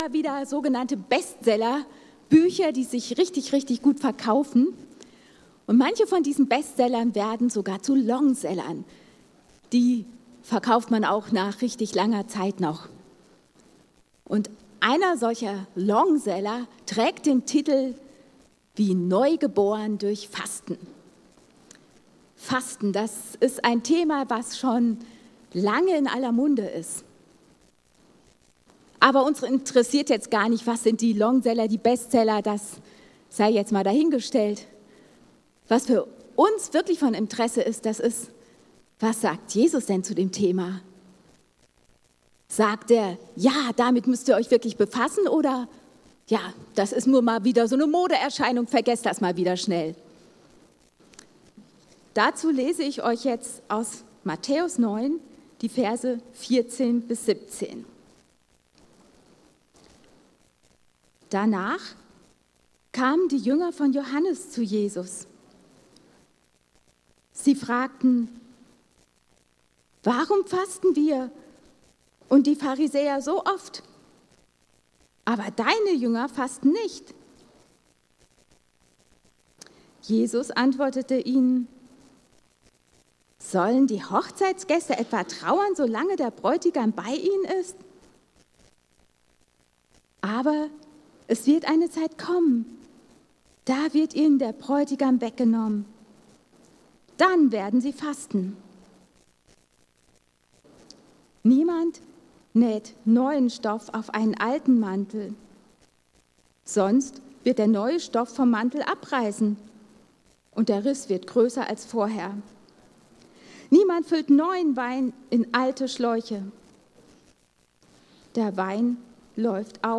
Die Freude des Fastens | Marburger Predigten